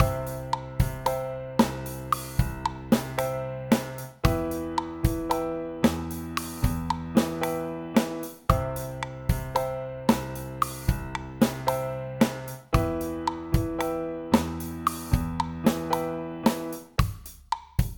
I've taken your piano sample into a project with 4/4 at about 113BPM, added JS and did a rough match to the drums you sent. I also added jam blocks to emphasize the 4/4 structure.
It sounds like you're doing a 3-3-2 pattern, which would account for the 3/4 feel (over most of it) but why the count comes out as 4/4,
piano44mix.mp3